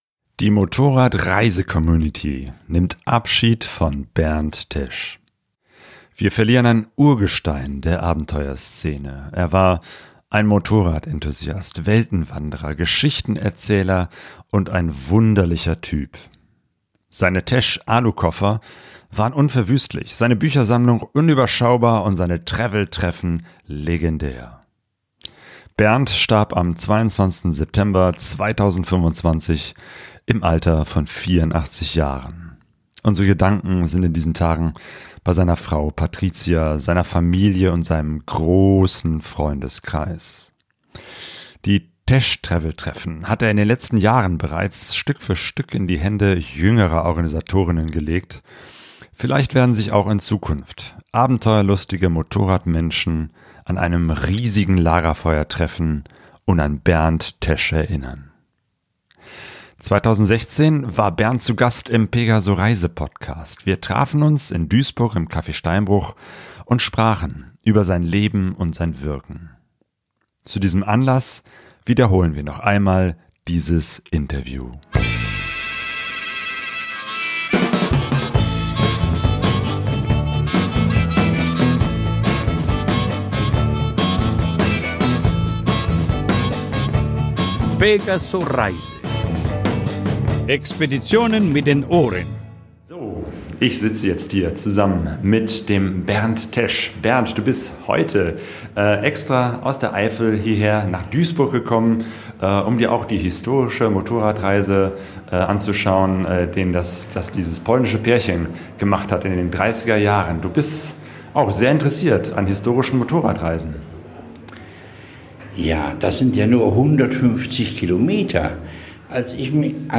Zu diesem Anlass wiederholen wir noch einmal dieses Interview.